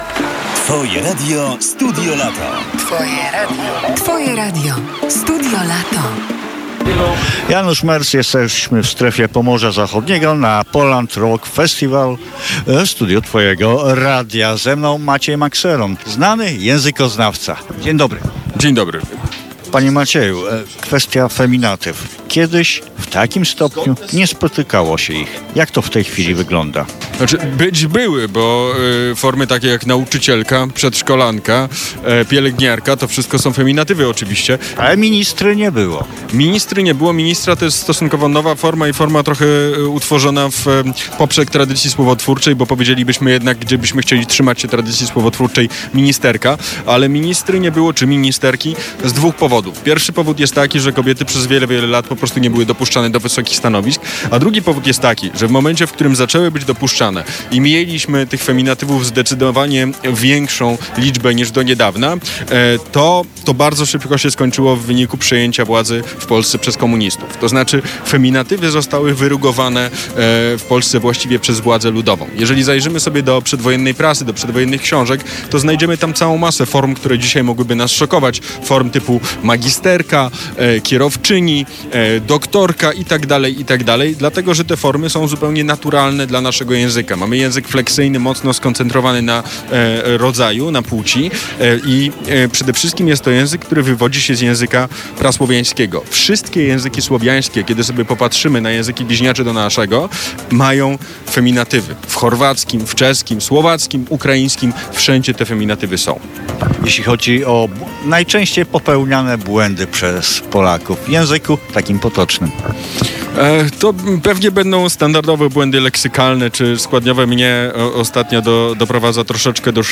Publikujemy wywiady, nagrane przez reporterów Twojego Radia bezpośrednio w Strefie Pomorza Zachodniego.